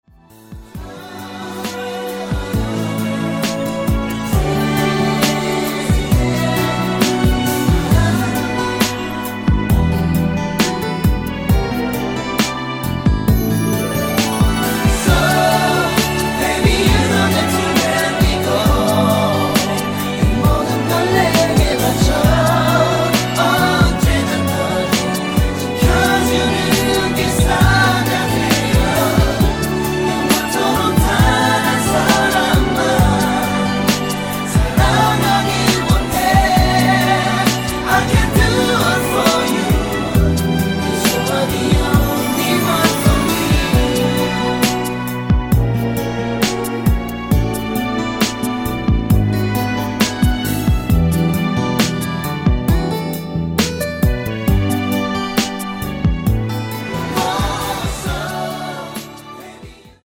코러스 포함 버젼 MR
<노란색 칠해진 부분이 코러스 추가된 구간 입니다.>
앞부분30초, 뒷부분30초씩 편집해서 올려 드리고 있습니다.
중간에 음이 끈어지고 다시 나오는 이유는